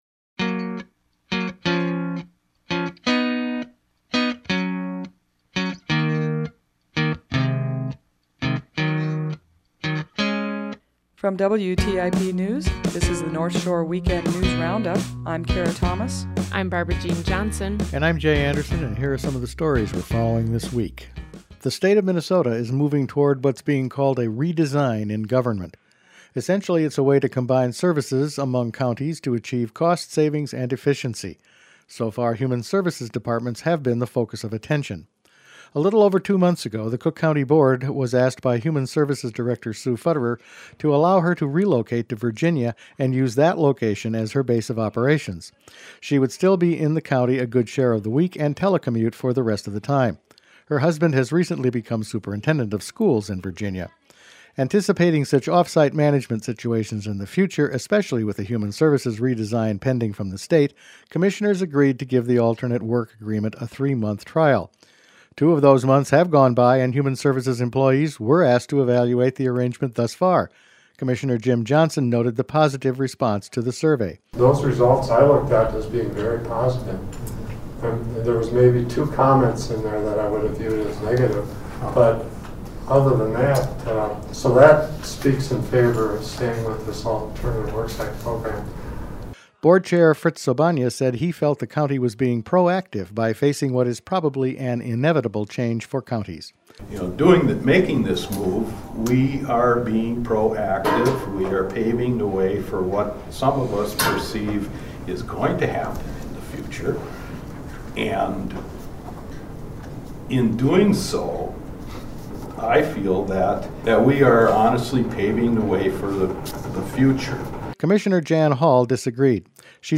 Each week the WTIP News Department provides a summary of the stories it has been following that week. Tune in to WTIP's North Shore Weekend Saturdays from 7:00 to 10:00 a.m. to hear the Wrap live or visit our website and listen at your convenience or subscribe to a podcast.